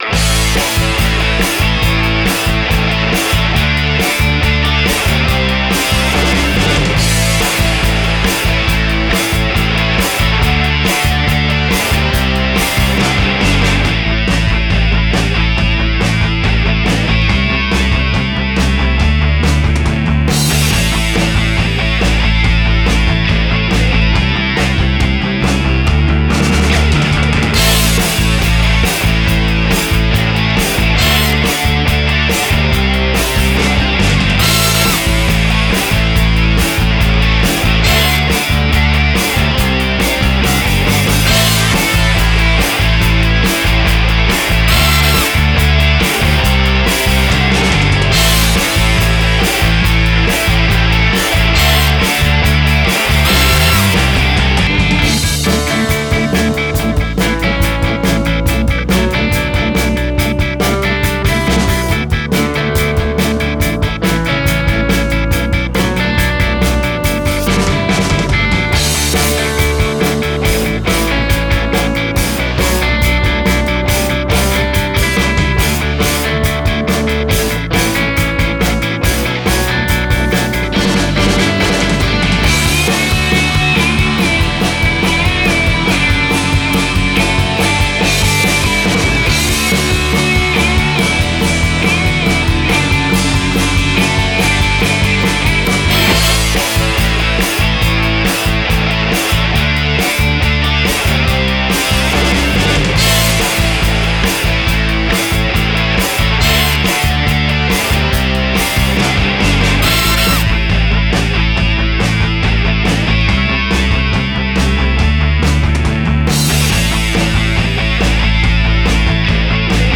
pop
guitarra eléctrica
rock
Sonidos: Música